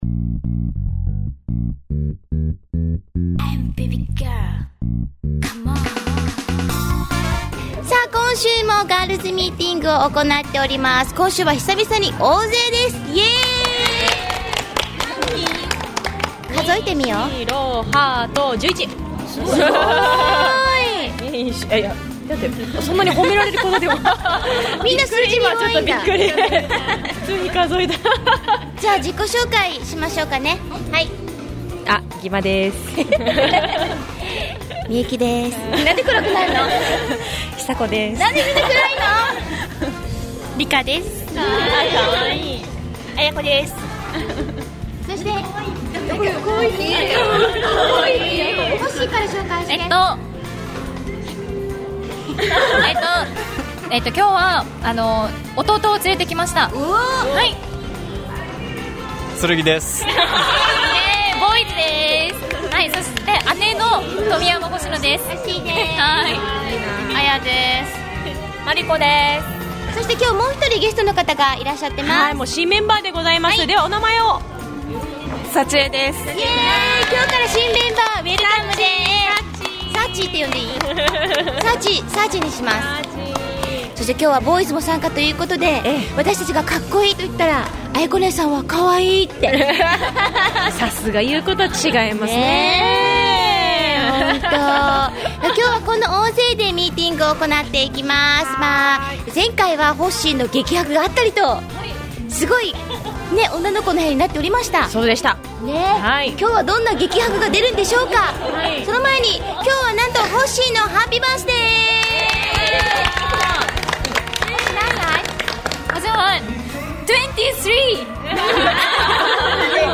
今回は１１名と久々の大人数のガールズ・ミィーティングです。